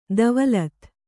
♪ davalat